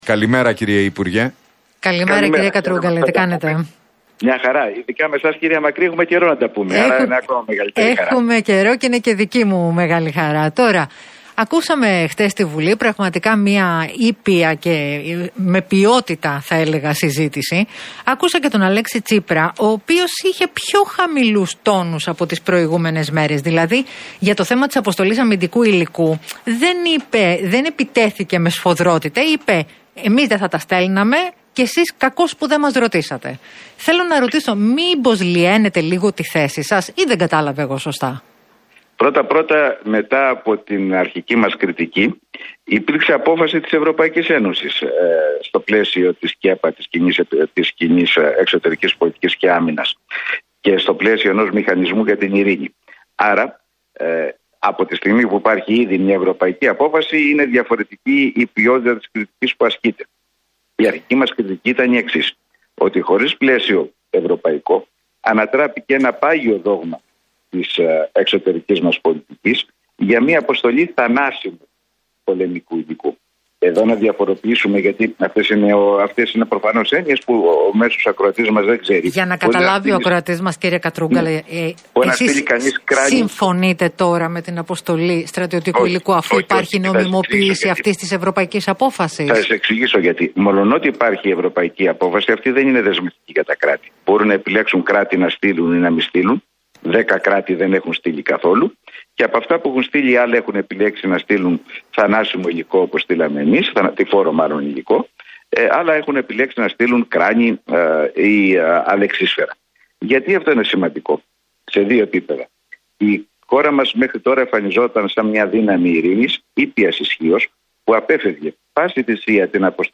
Ο βουλευτής του ΣΥΡΙΖΑ και τομεάρχης Εξωτερικών Γιώργος Κατρούγκαλος δήλωσε σχετικά με τις εξελίξεις με την εισβολή της Ρωσίας στην Ουκρανία στον Realfm 97,8